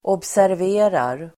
Uttal: [åbsärv'e:rar]